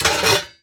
metal_lid_movement_impact_08.wav